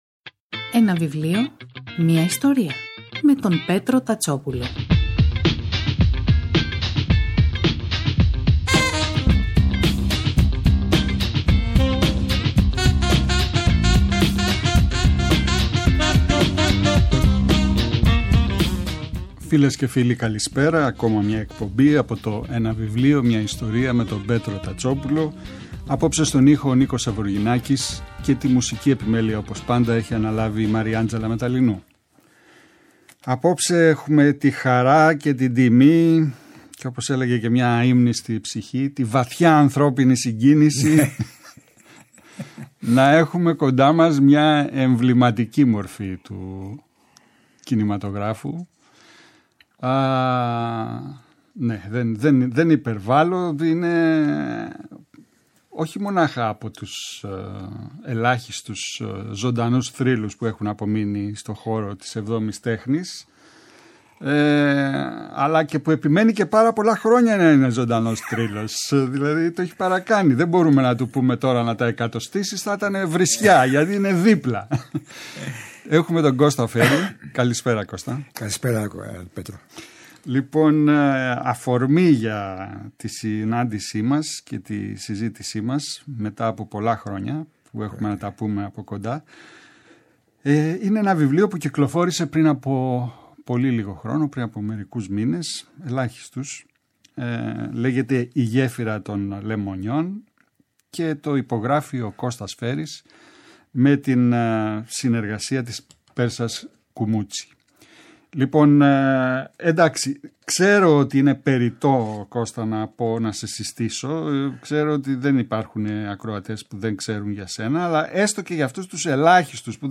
Το Σάββατο 23.09 καλεσμένος ο Κώστας Φέρρης για το αυτοβιογραφικό βιβλίο του ” Η γέφυρα των λεμονιών ” (εκδόσεις Ποταμός).